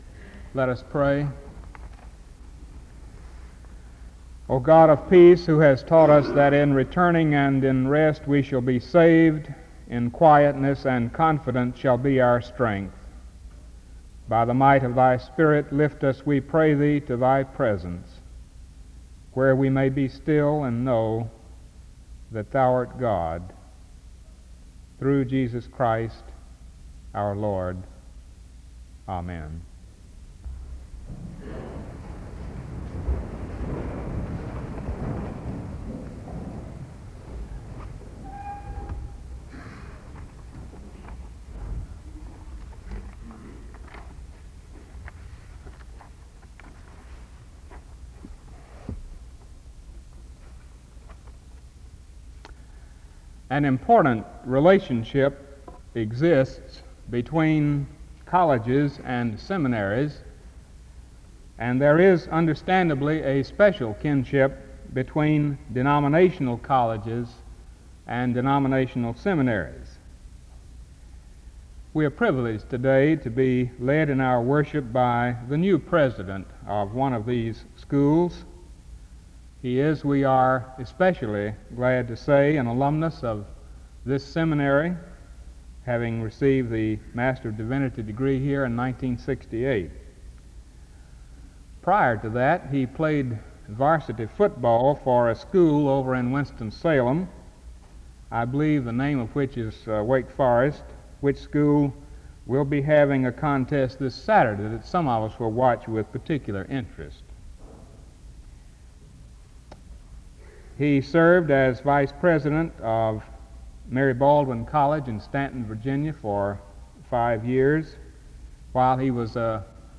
SEBTS Chapel
The service opens with prayer from 0:00-0:30. An introduction to the speaker is given from 0:53-3:06. A responsive reading takes place from 3:08-4:15.